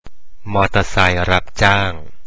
maaw dtuuhr sai